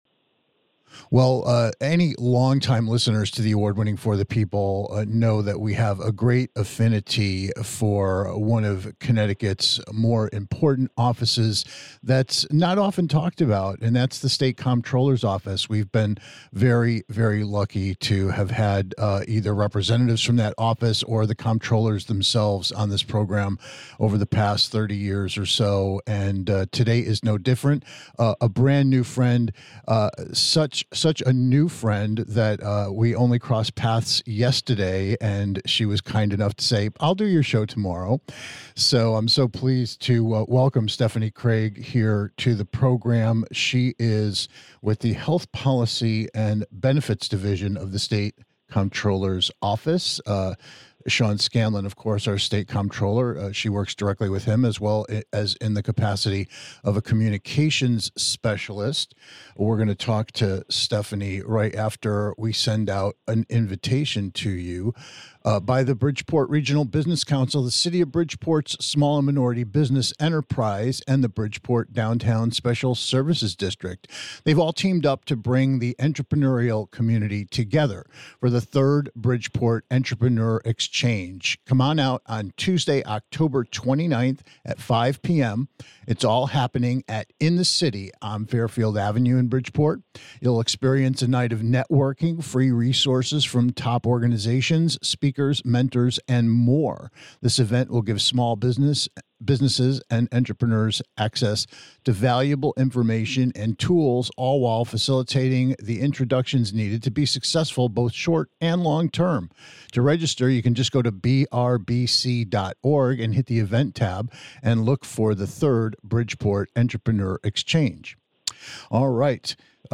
If you're paying too much for your prescriptions and certain OTC products, tune into our conversation with a representative from the Connecticut State Comptroller's Office, which is ramping up promotion of the ArrayRX Discount Drug and Prescription program, that can deliver up to 80% savings to any state resident for many of their pharmaceutical needs.